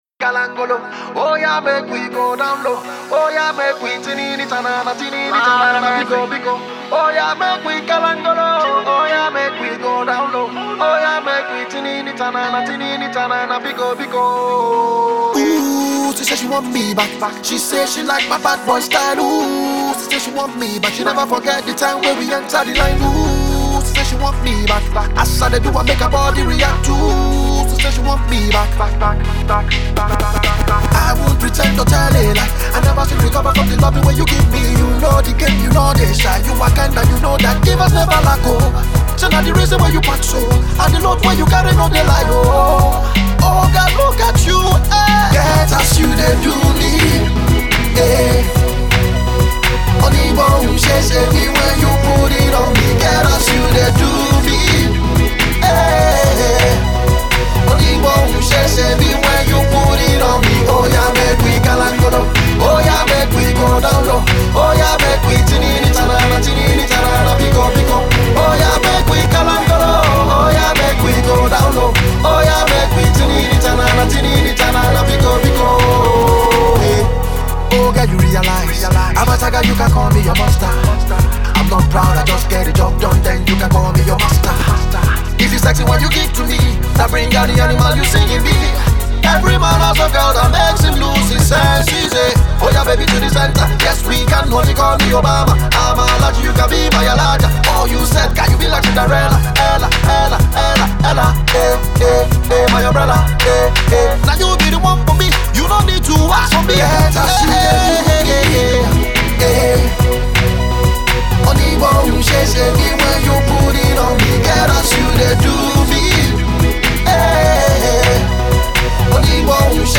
on his spanking new Afro-Pop single
Patois-spitting